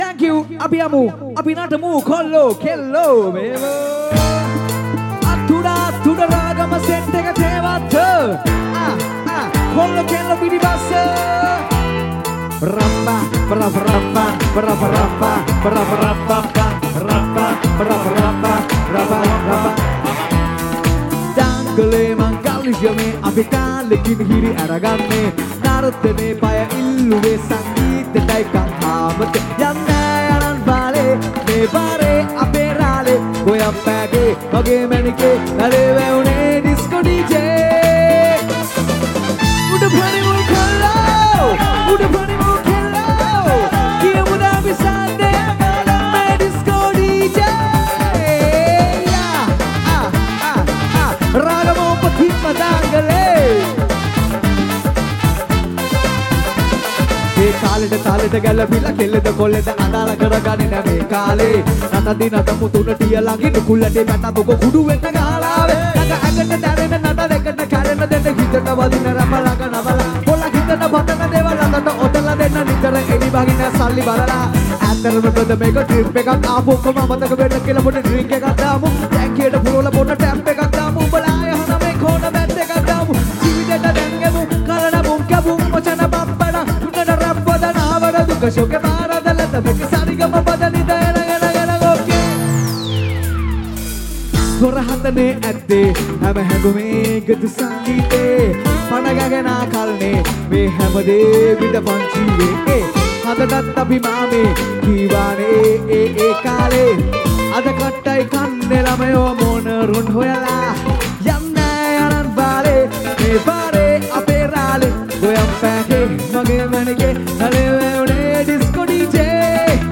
Live in Narangodapaluwa